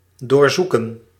Ääntäminen
IPA : /swiːp/ US : IPA : [swiːp]